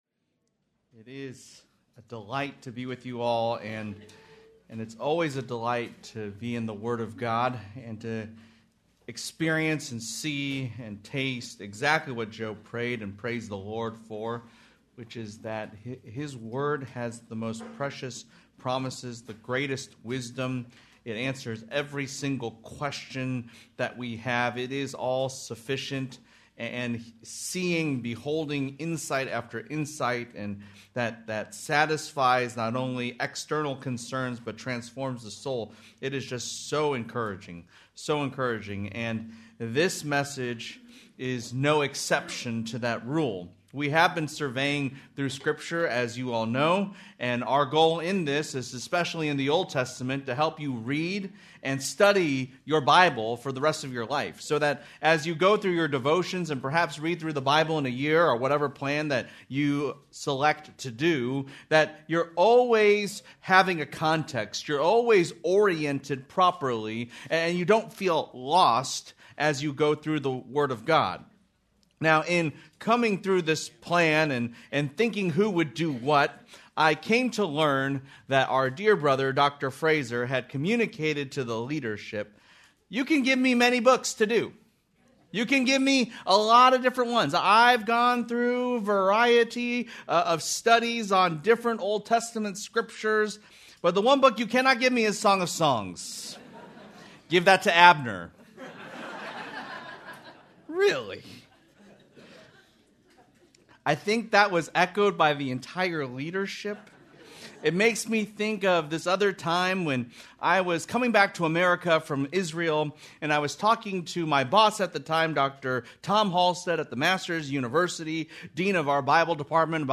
Due to technical difficulties this sermon is incomplete.